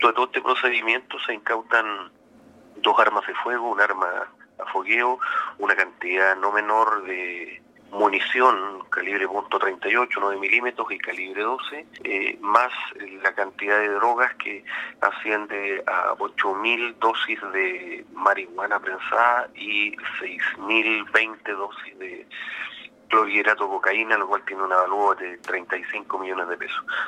El Mayor detalló que en el contexto del procedimiento policial desarrollado por ese caso, se terminó con la incautación de armas de fuego y cerca de 35 millones pesos en droga.